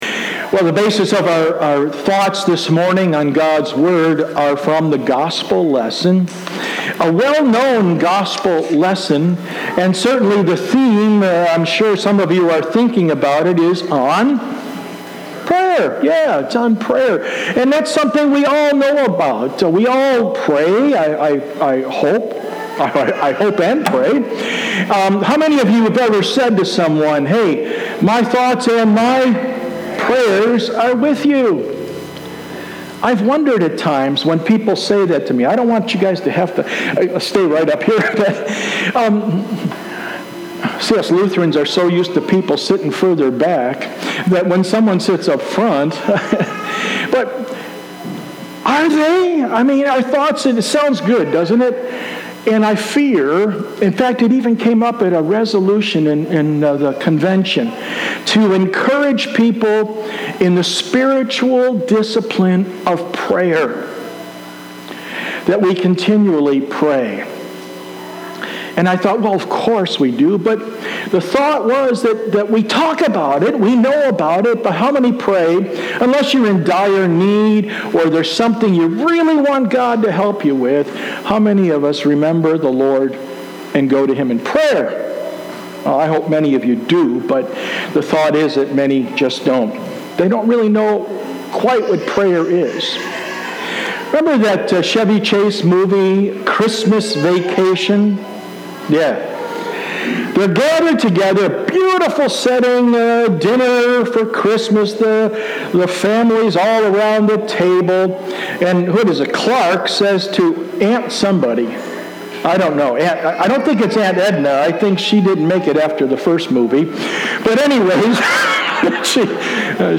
Sermon 7-28-19